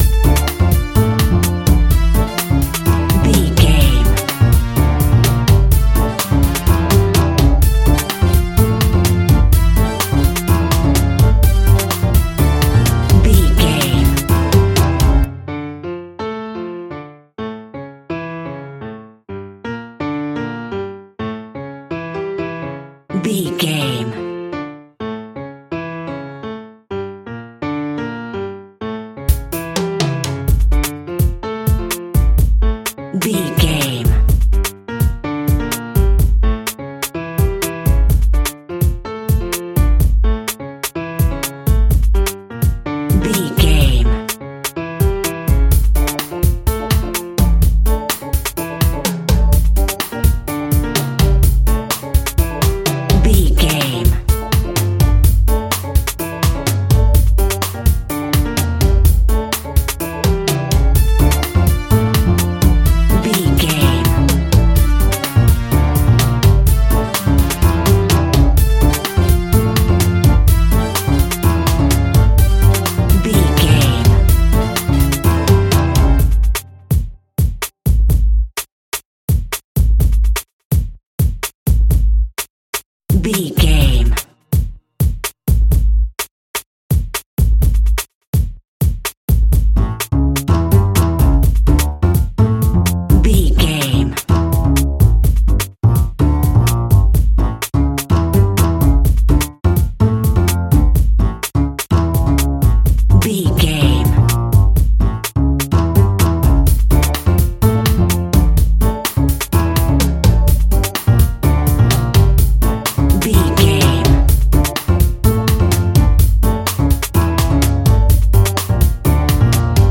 Aeolian/Minor
Fast
groove
salsa
energetic
electric guitar
bass guitar
drums
hammond organ
fender rhodes
percussion